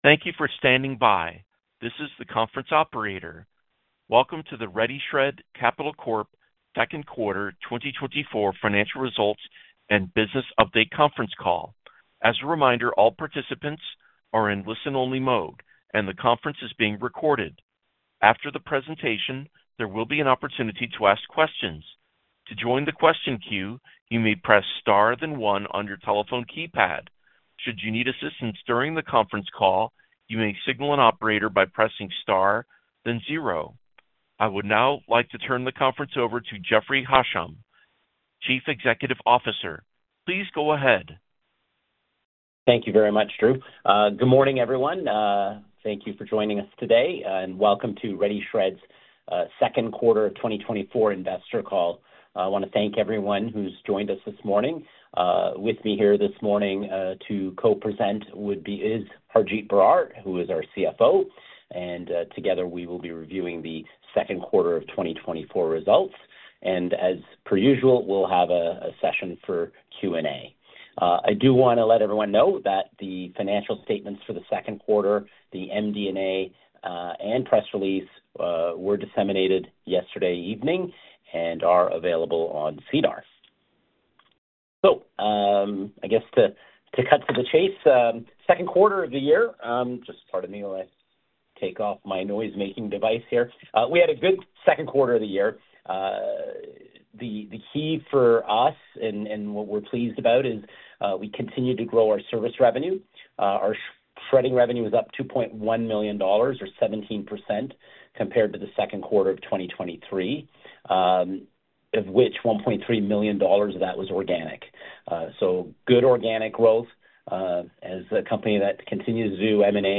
Conference Call - Third Quarter 2024 | Financial Results